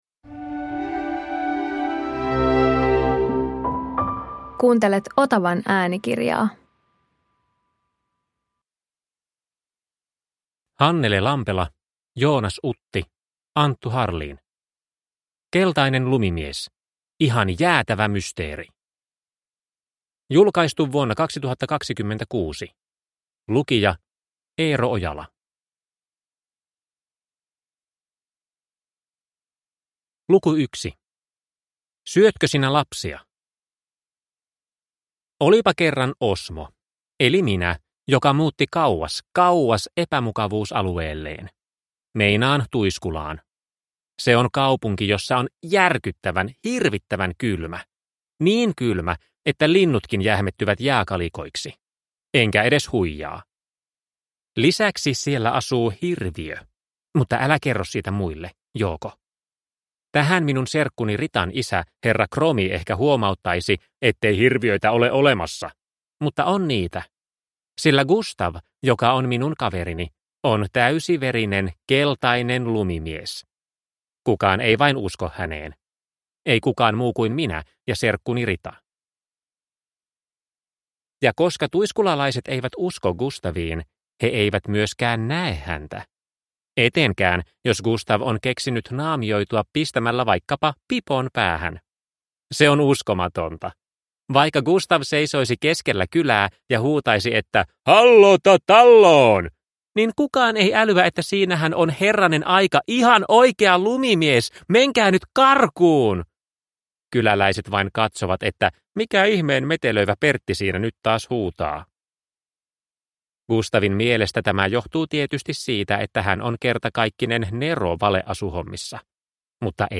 Keltainen lumimies – ihan jäätävä mysteeri! – Ljudbok